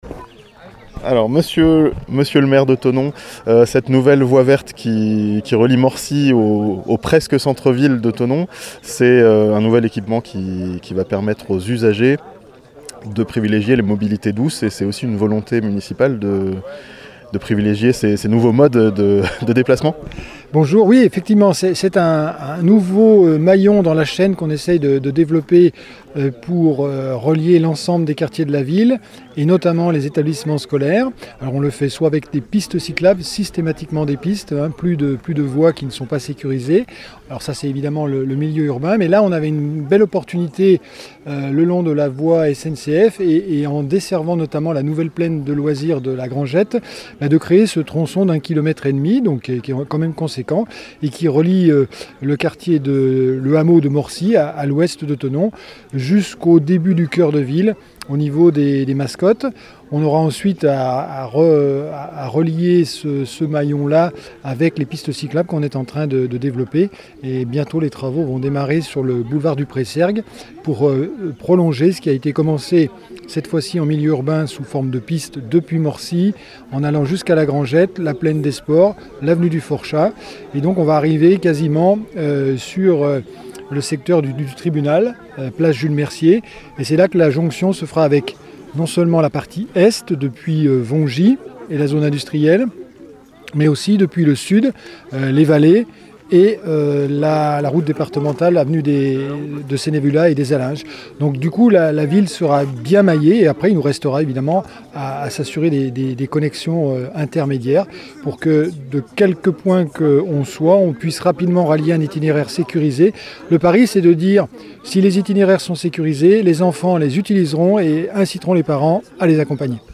Une nouvelle portion de Voie Verte inaugurée à Thonon (interview)